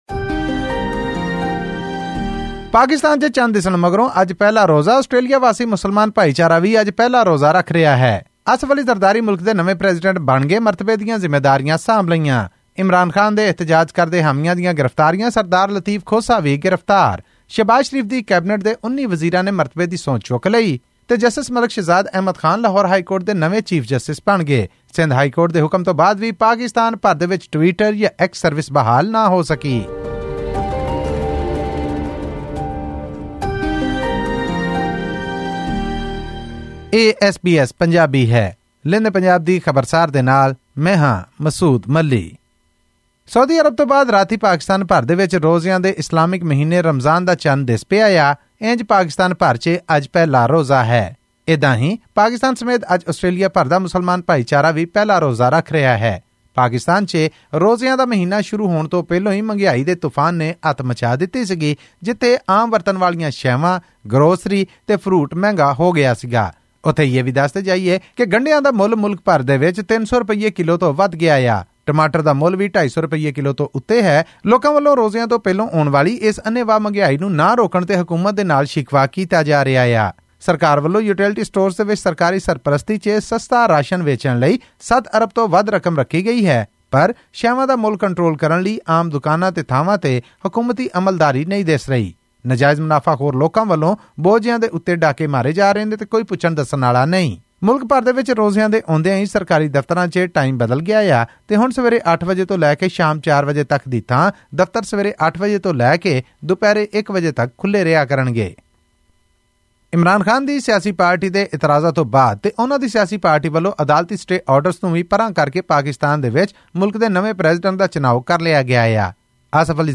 ਇਹ ਅਤੇ ਹੋਰ ਖਬਰਾਂ ਲਈ ਸੁਣੋ ਆਡੀਓ ਰਿਪੋਰਟ...